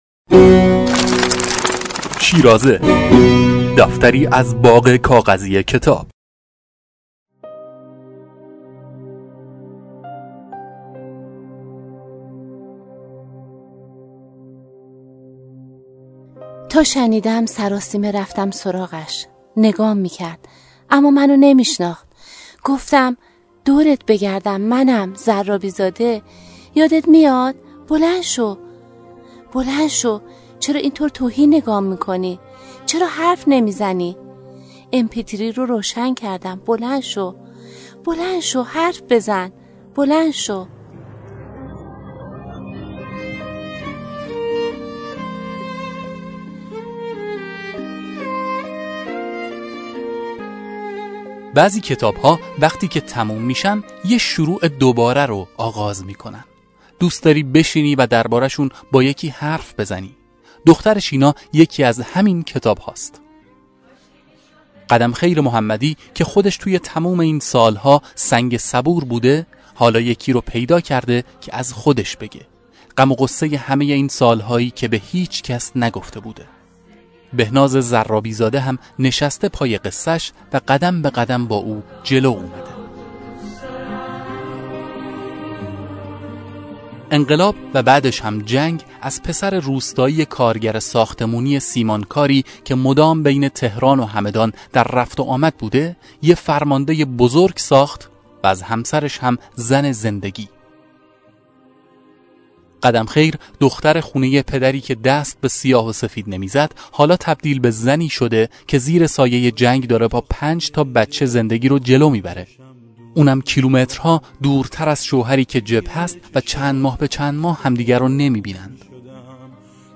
به گزارش خبرگزاری تسنیم، ‌ویژه‌برنامه رادیویی شیرازه با معرفی کتاب «دختر شینا» هم‌زمان با هفته دفاع مقدس، از رادیو فرهنگ به‌صورت ویژه و در چندین نوبت، پخش شد.